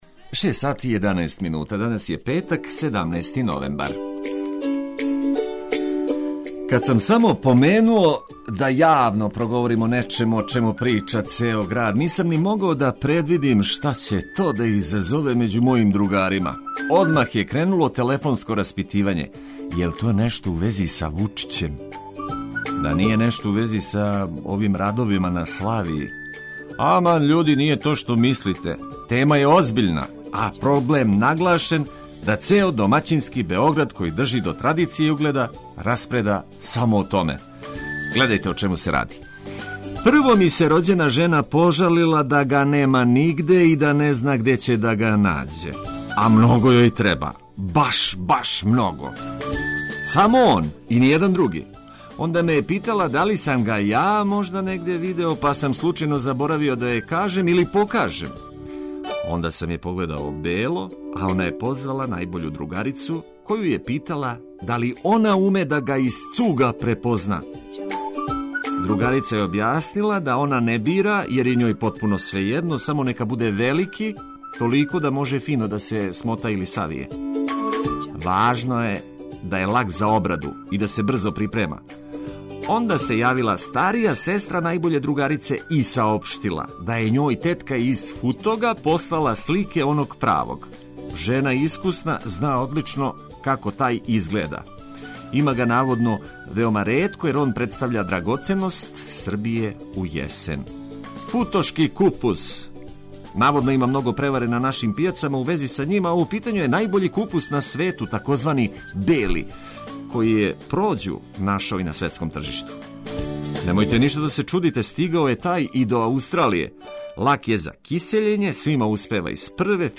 За ваше буђење уз корисне вести и музику за устајање задужени смо ми.